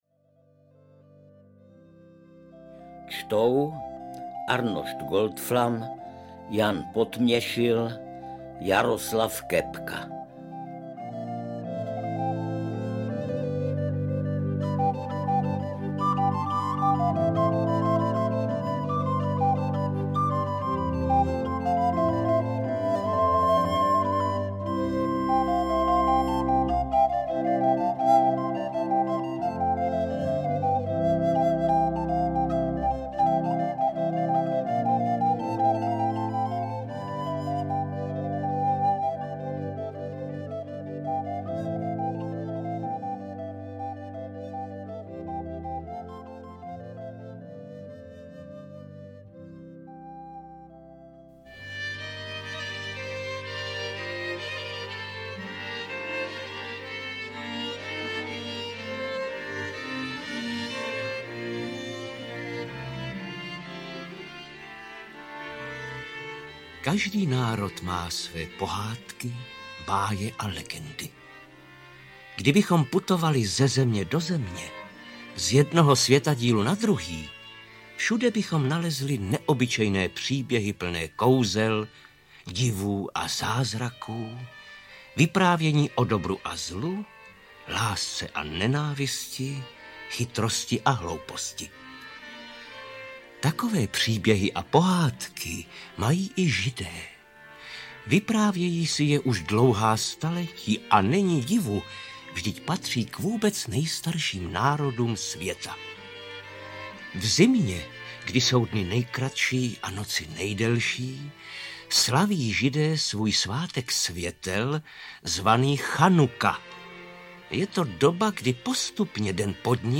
Ukázka z knihy
• InterpretArnošt Goldflam, Jaroslav Kepka, Jan Potměšil